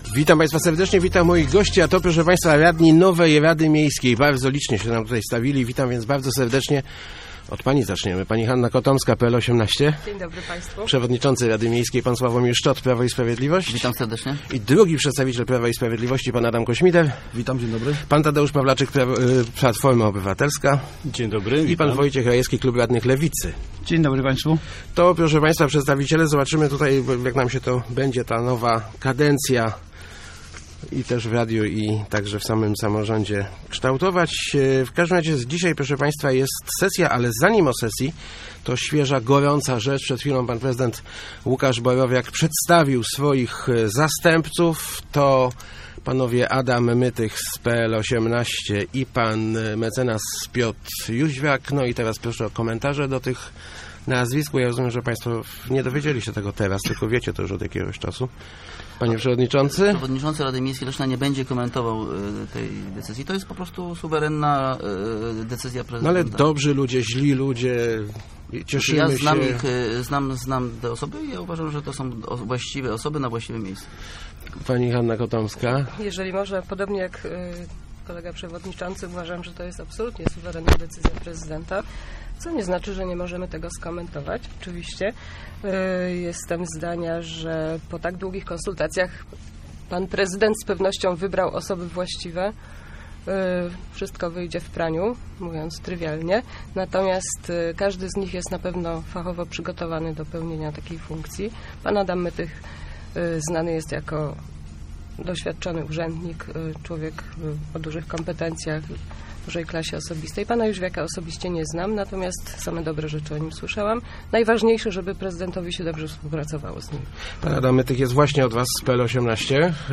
Na dzisiejszej, trzeciej sesji radni Leszna zajmą się wyborem składów komisji. Już z rozmowy w studiu Radia Elka widać, że może dojść do syskusji na temat ich obsadzenia.